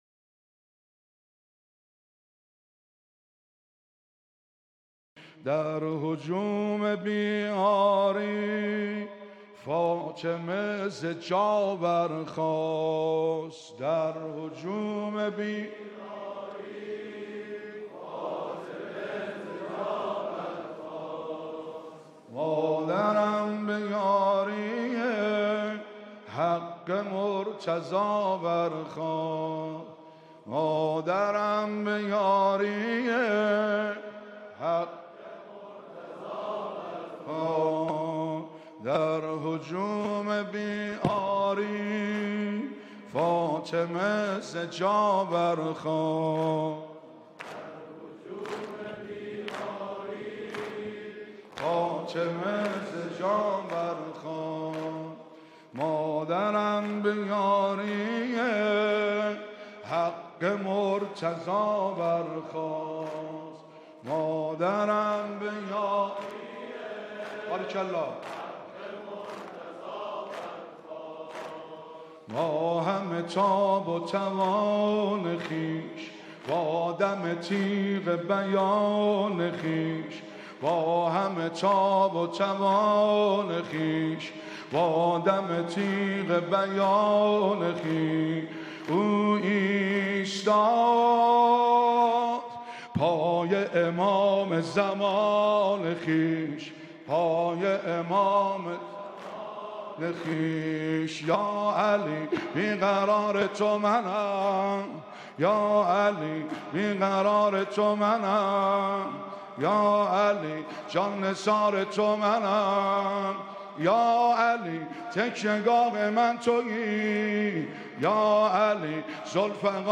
پنجمین شب مراسم عزاداری حضرت زهرا سلام‌الله‌علیها در حسینیه امام خمینی(ره)
مداحی